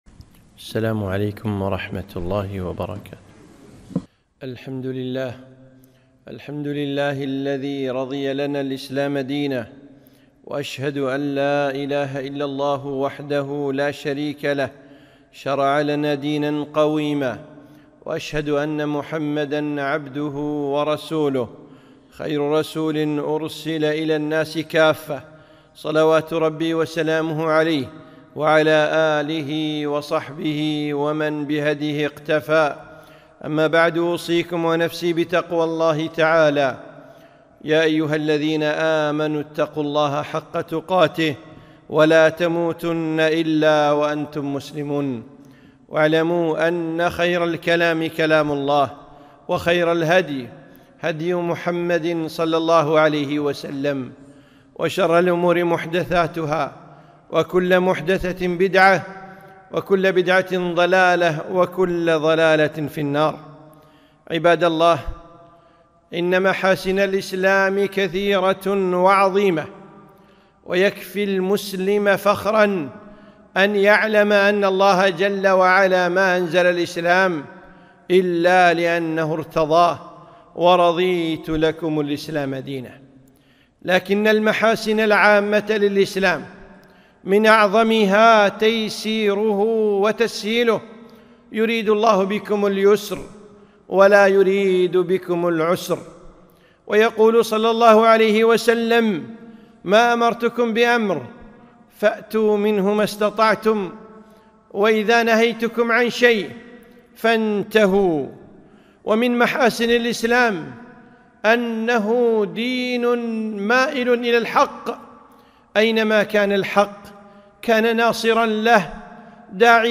خطبة - محاسن الإسلام